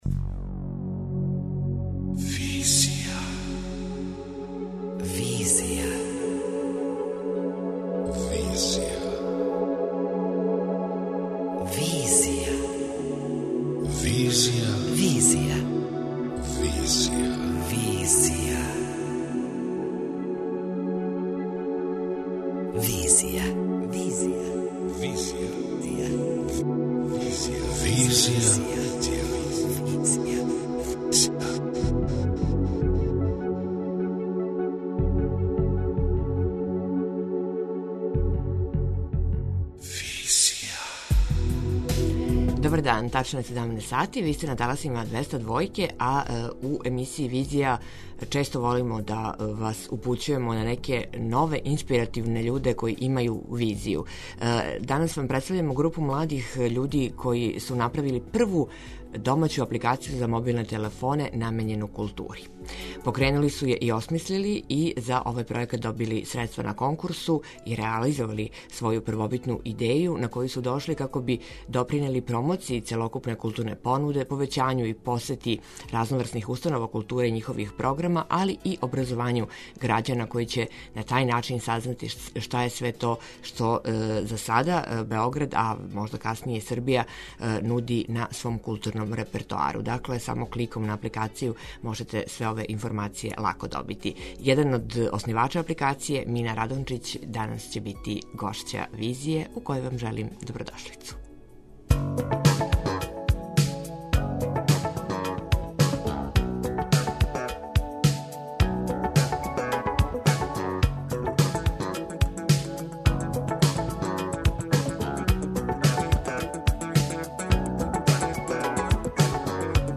преузми : 27.93 MB Визија Autor: Београд 202 Социо-културолошки магазин, који прати савремене друштвене феномене.